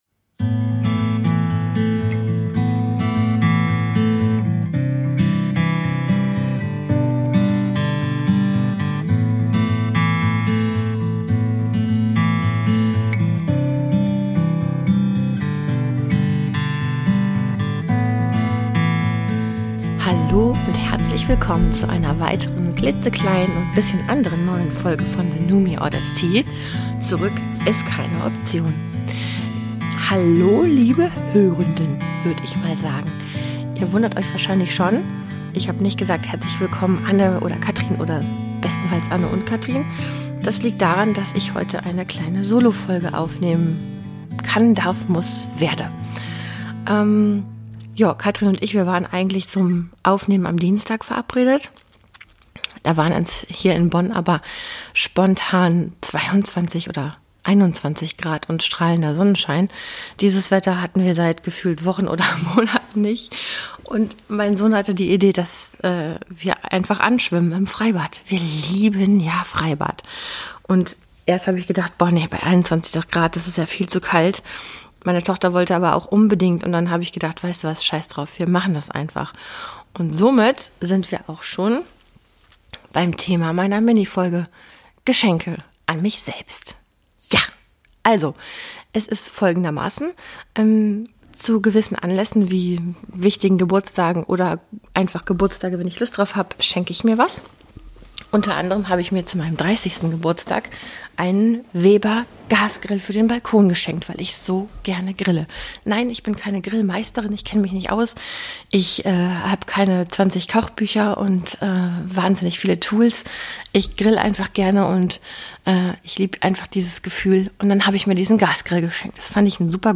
Eine kurze Solo-Folge